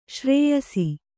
♪ śrēyasi